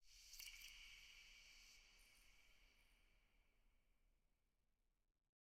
eyeblossom_open_long.ogg